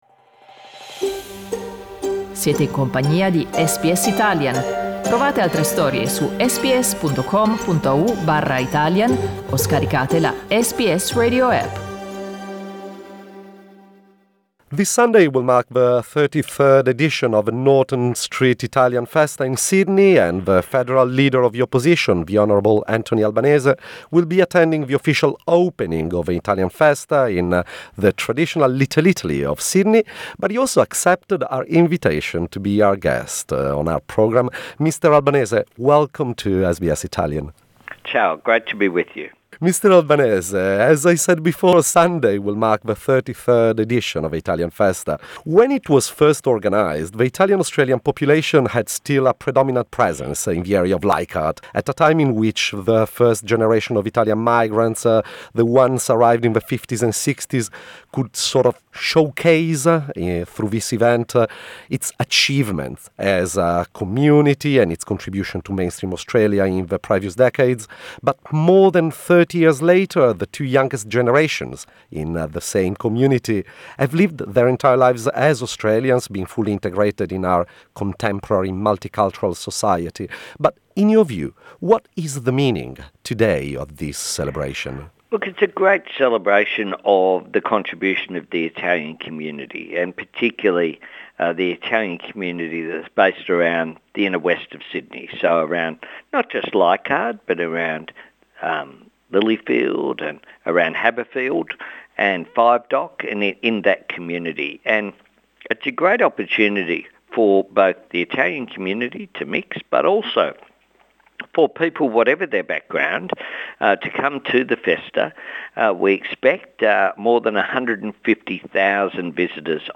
Federal Opposition Leader, Anthony Albanese, will be attending the official opening of the Norton Street Italian Festa on Sunday. SBS Italian spoke with him to talk about the meaning of this celebration today and what is the legacy of Italian migration.